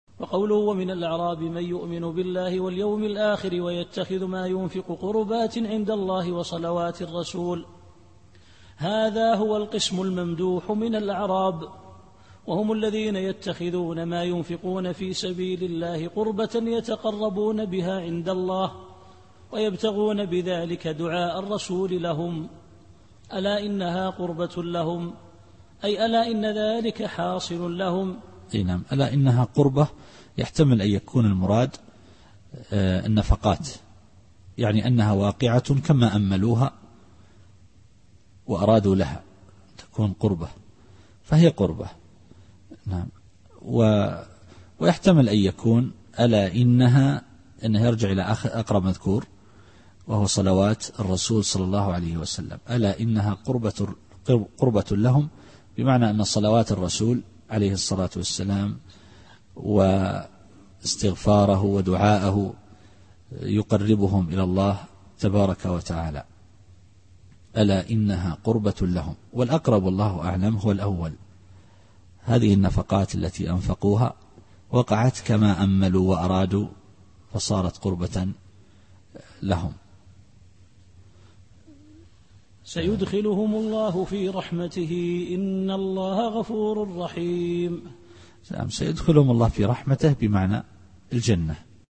التفسير الصوتي [التوبة / 99]